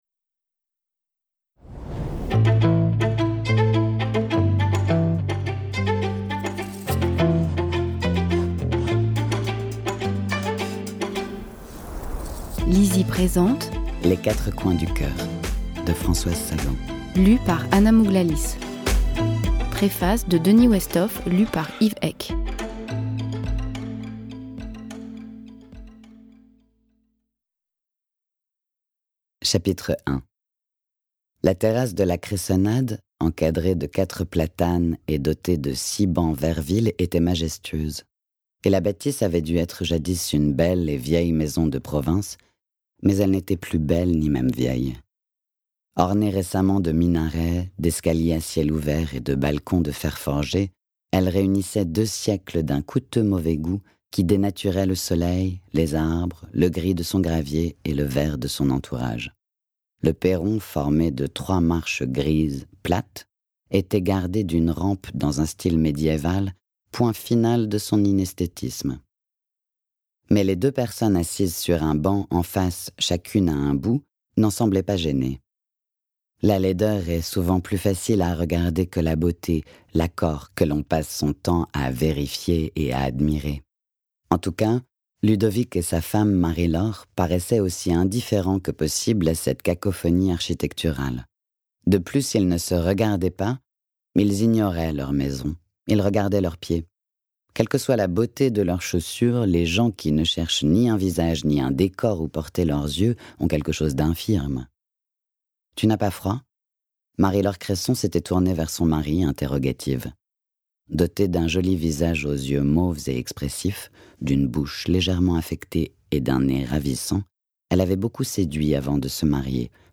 Extrait gratuit - Les quatre coins du cœur de Françoise Sagan